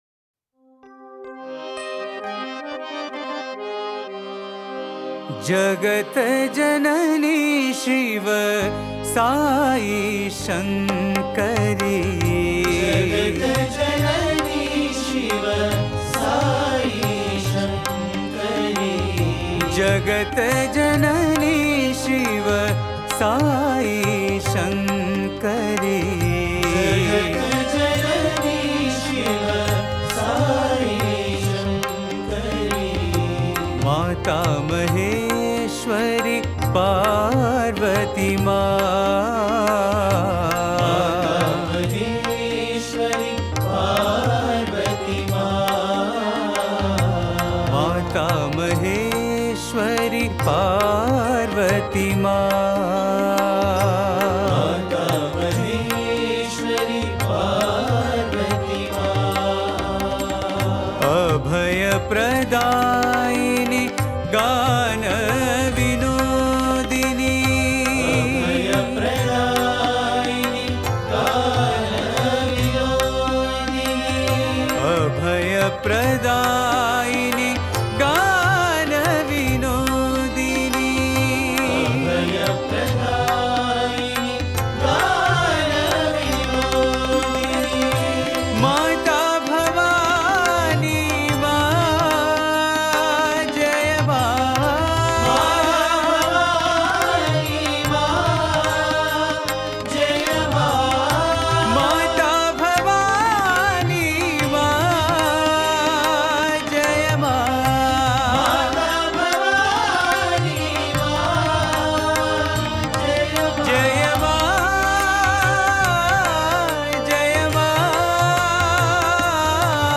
Author adminPosted on Categories Devi Bhajans